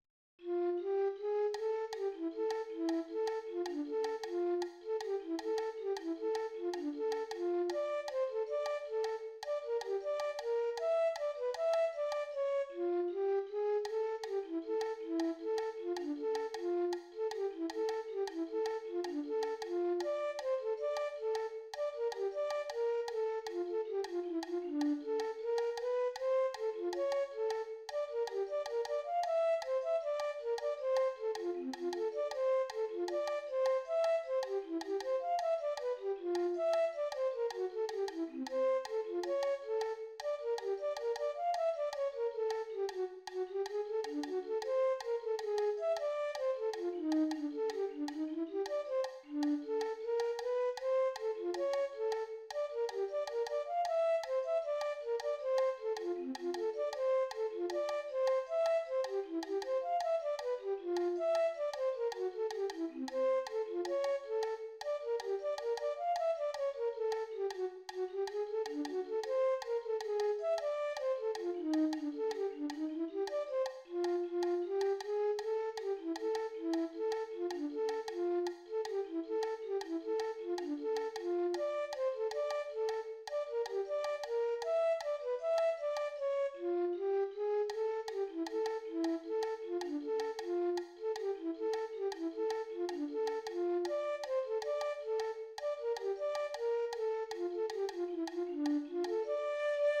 Pattern 1 is known in Cuba as son clave.
Example in a Pixinguinha choro music